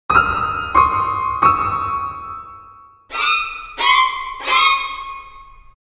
hunter spawn cue Meme Sound Effect
Category: Games Soundboard
hunter spawn cue.mp3